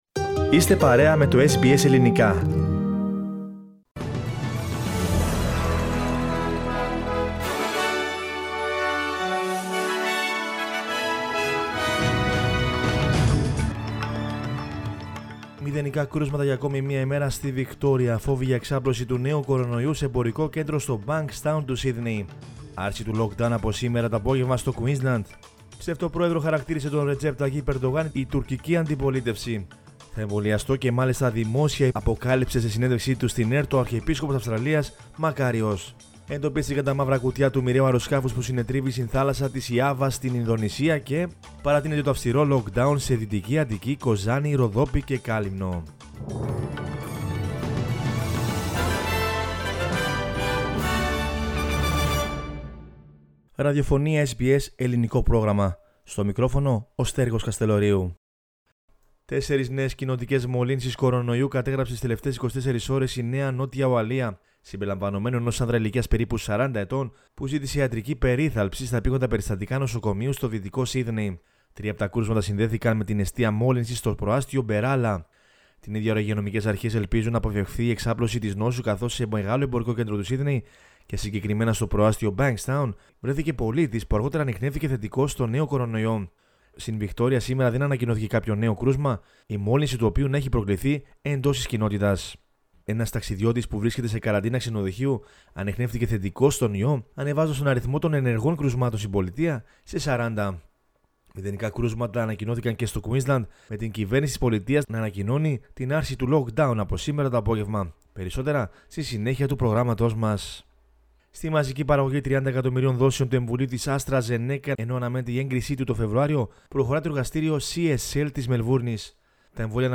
News in Greek from Australia, Greece, Cyprus and the world is the news bulletin of Monday 11 January 2021.